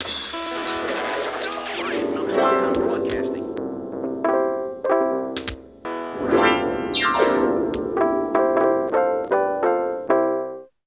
Here is the Welcome.cji Instrument File: As you move the mouse over five different areas, you can play the following: Top : CyberJammer Logo - sound effects Left : Standard six string guitar Center : Drums Right : Concert Harp in C Major Bottom : A Rhodes keyboard Here are some audio samples of how the CyberJammer Welcome demo sounds, as you move the mouse around, and play its chords: Welcome Sample, as WAV (1.9 MB) .
01WelcomeSample.wav